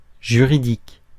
Ääntäminen
Ääntäminen France: IPA: [ʒy.ʁi.dik] Haettu sana löytyi näillä lähdekielillä: ranska Käännös Konteksti Ääninäyte Adjektiivit 1. judicial laki US 2. forensic vanhahtava Muut/tuntemattomat 3. legal US Suku: f .